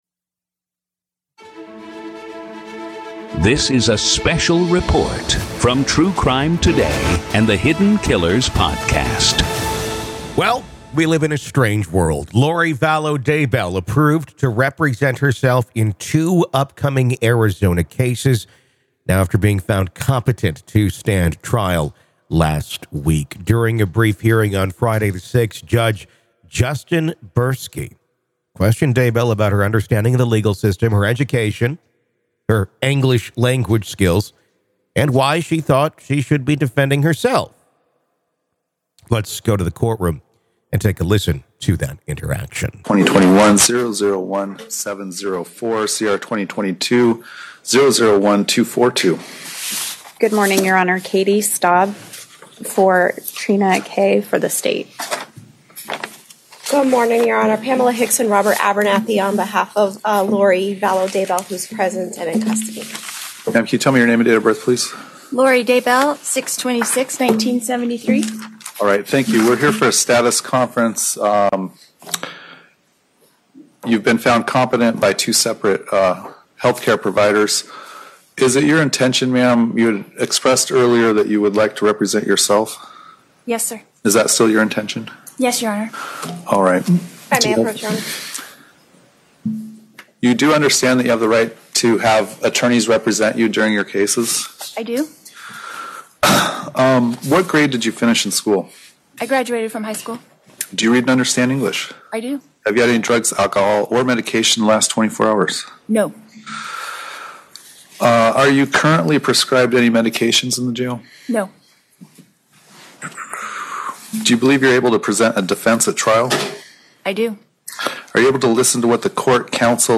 Hear the dramatic courtroom exchange as Lori pleads her case for self-representation, and listen to the judge’s measured response. Join us as we break down the legal implications of her request, the challenges of self-representation, and what this could mean for her highly publicized trial.